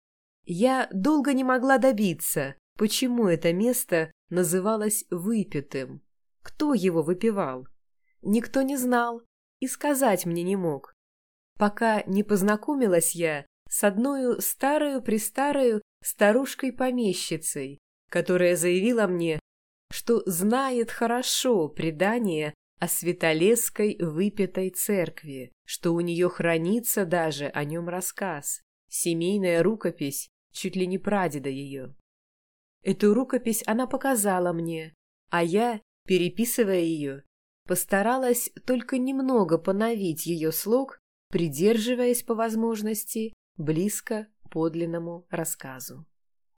Аудиокнига Святолесские певцы | Библиотека аудиокниг